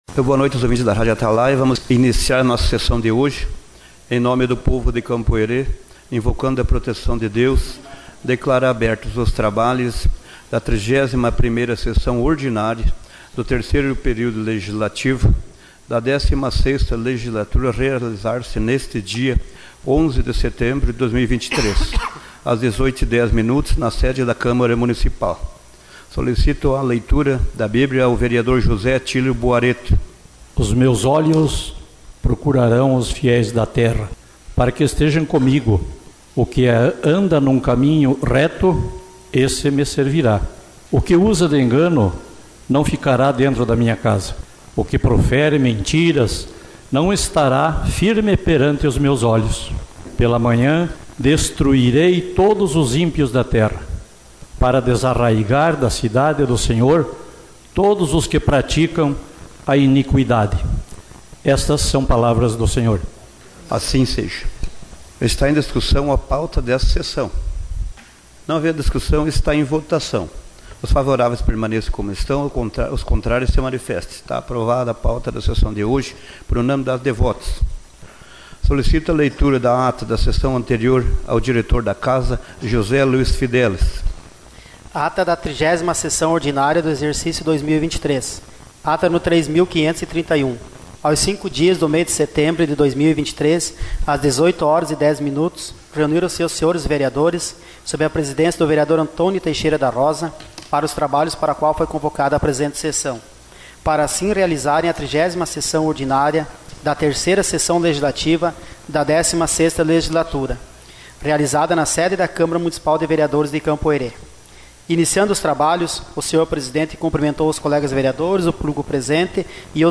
Sessão Ordinária 11/09